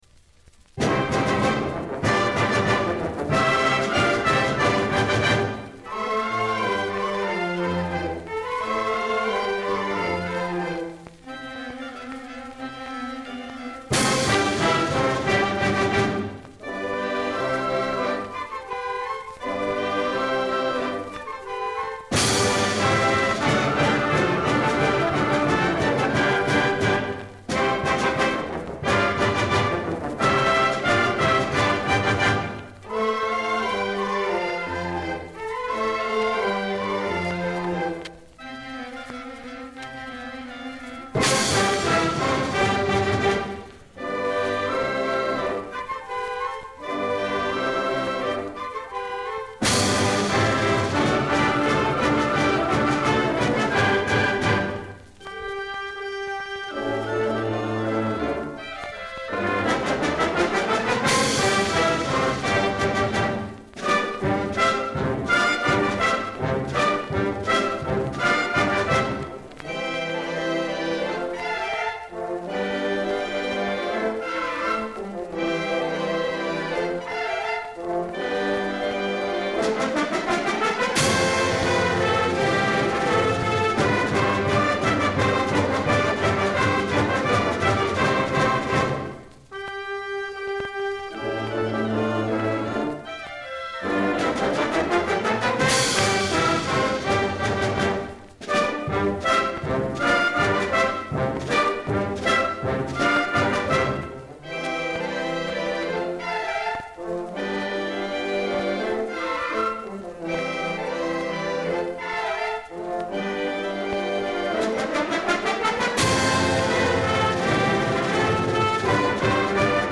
1962 Spring Concert Band, Daniel Webster Jr High School, Stockton, California.
SPRING_CONCERT_BAND_-_03._MILITARY_SYMPHONY_IN_F_ALLEGRO_.MP3